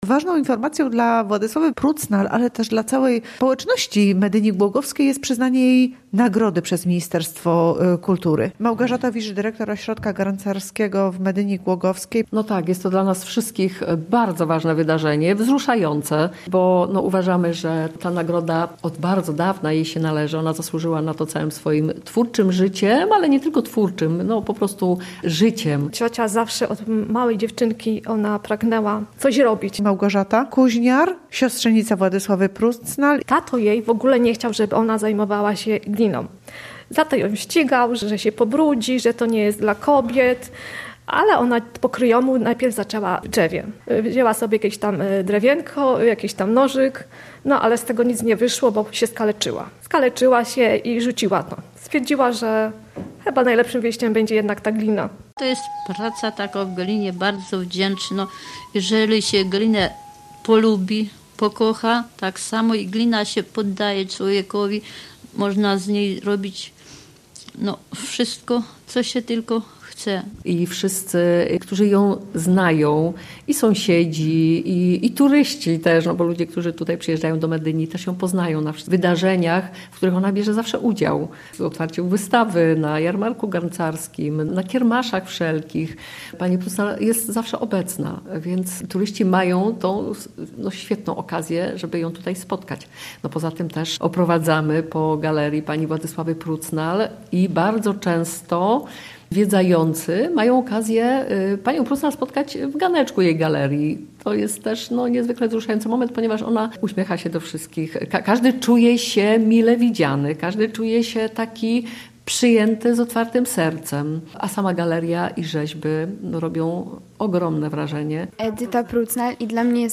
W relacji został wykorzystany fragment archiwalny z jej wypowiedzią.